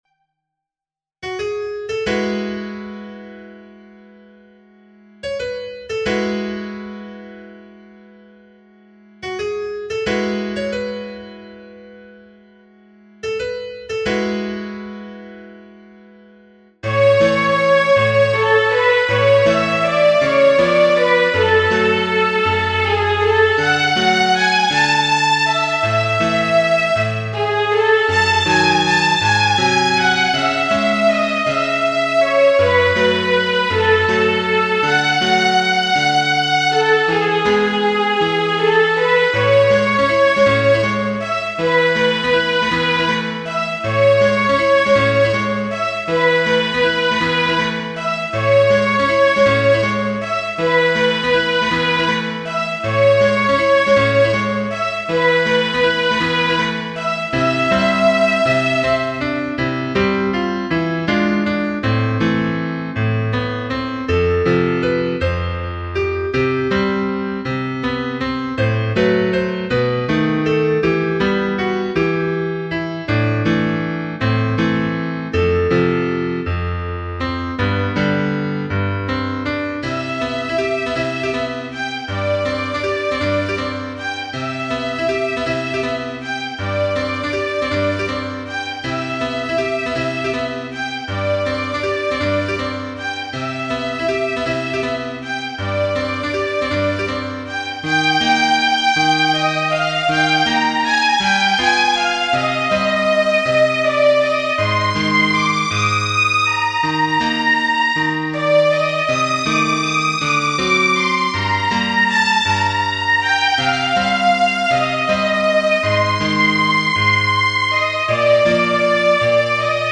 Genere: Moderne